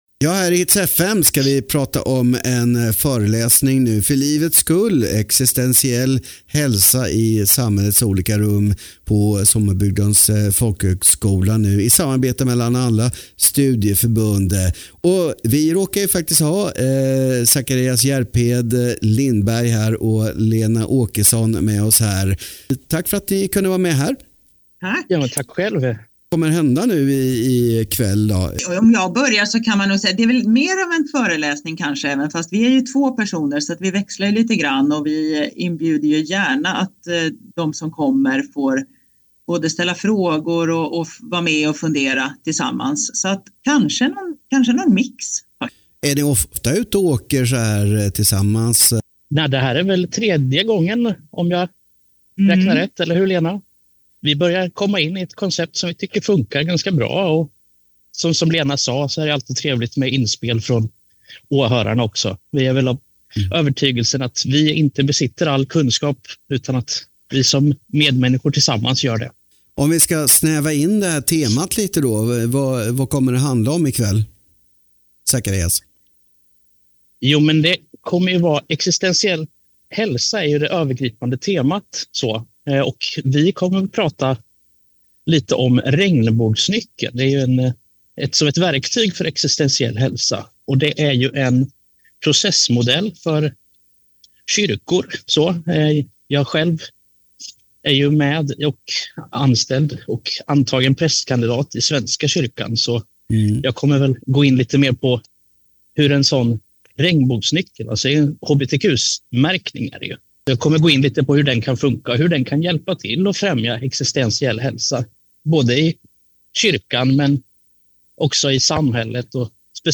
Del av radioprogrammet